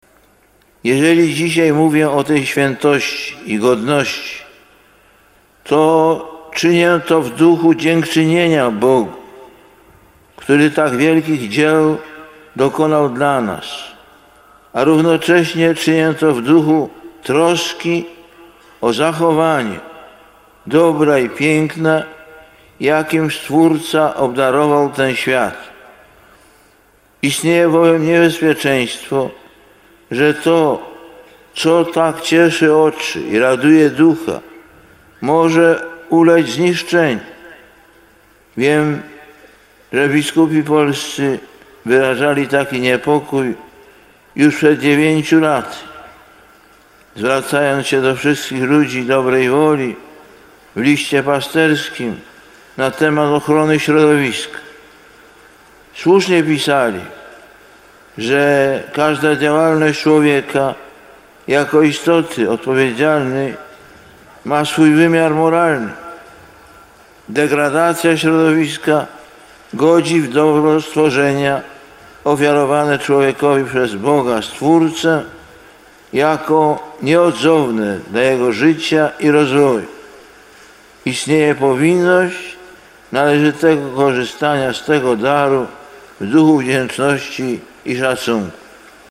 Lektor: (Z homilii w Zamościu - nagranie): „Wedle tego biblijnego zapisu, Bóg w kolejnych dniach stworzenia patrzył niejako na dzieło swojego zamysłu i widział, że wszystko, co uczynił było dobre.